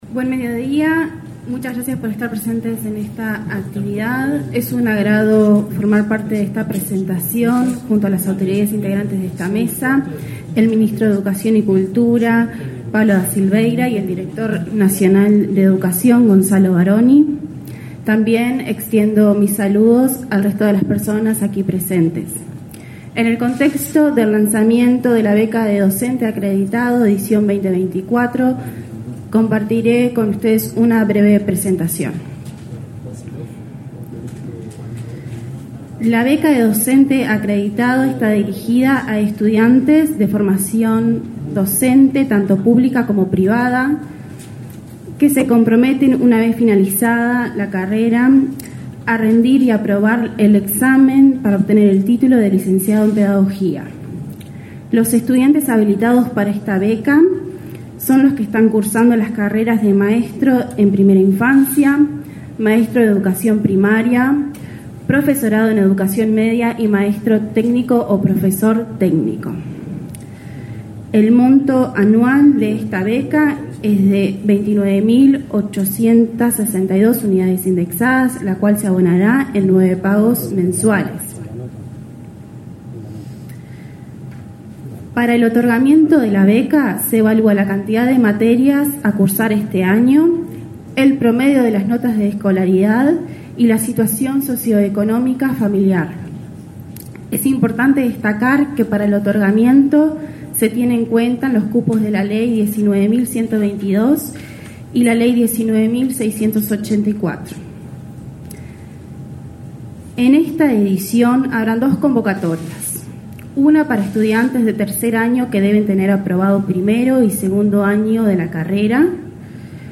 Acto por el lanzamiento de la beca Docente Acreditado para estudiantes de Formación Docente